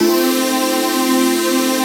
ATMOPAD18.wav